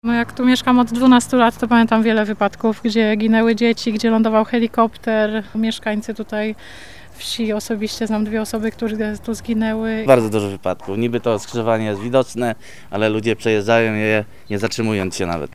ludzie-o-wypadkach.mp3